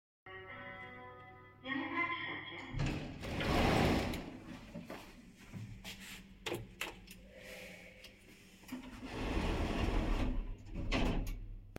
Nadjeżdżająca winda wydawała charakterystyczny dźwięk statku kosmicznego lądującego na Księżycu.